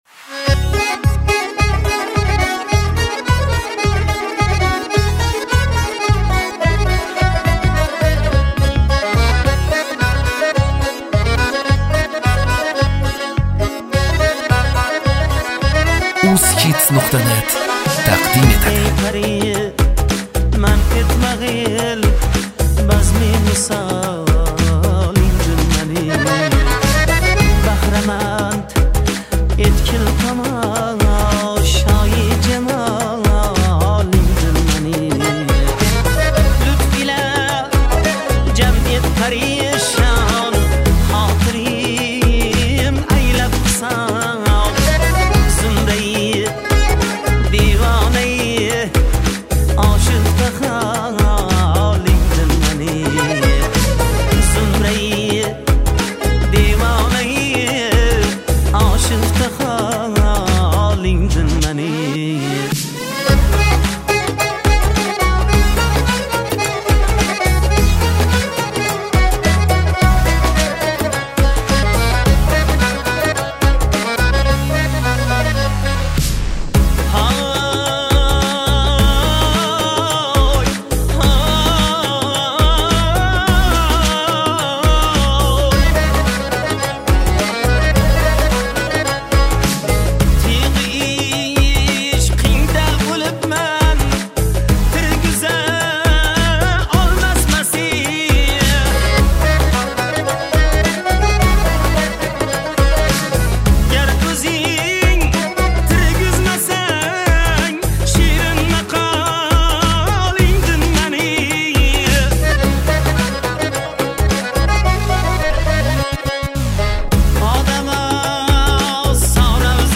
Ўзбекистон мусиқаси